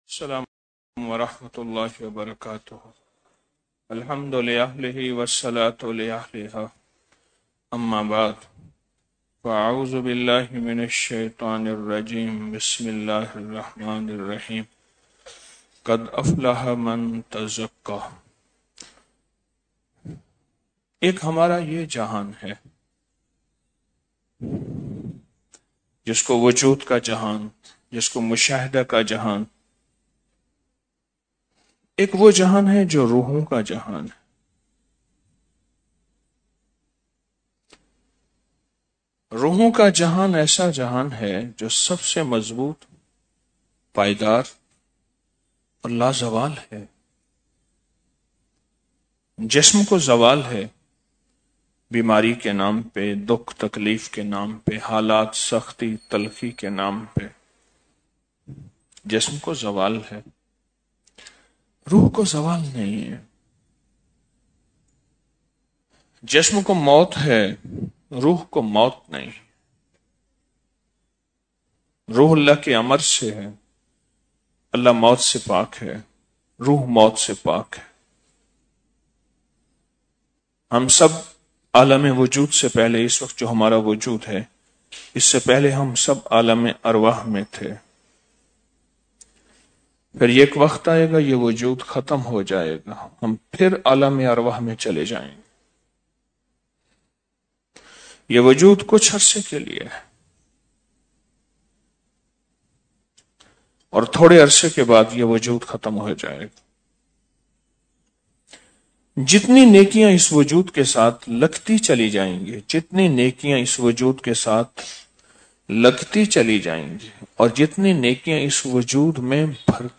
Audio Speech - 08 Sawa Crore Surah Ikhlas | Jadu Jinnat Se Hifazat | 31 Oct 2024